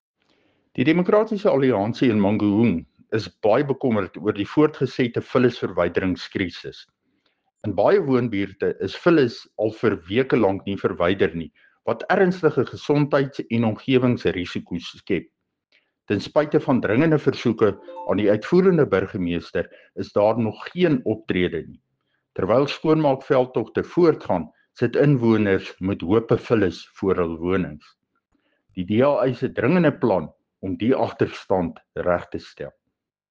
Afrikaans soundbites by Cllr Dirk Kotze and Sesotho soundbite by Jafta Mokoena MPL.